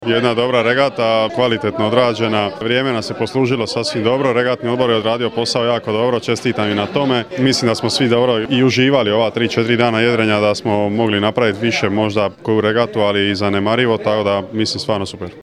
MP3 izjava